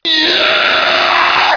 zombie_scream_2.wav